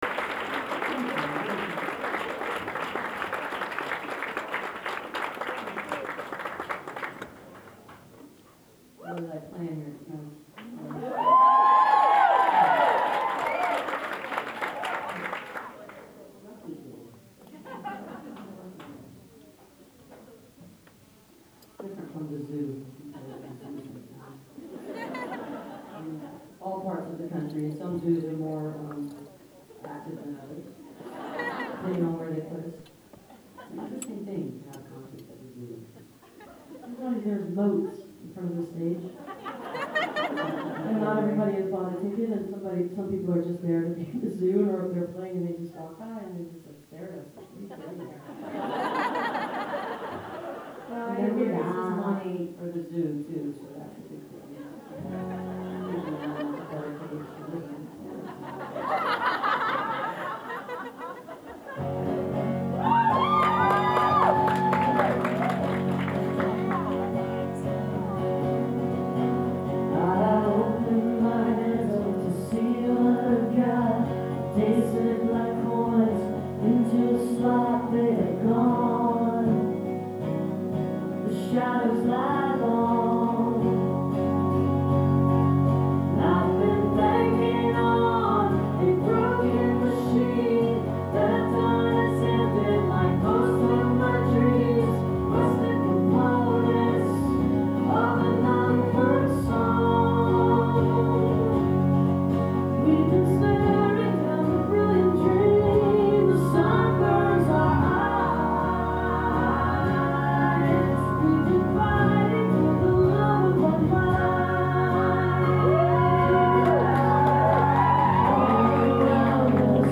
birchmere music hall - alexandria, virginia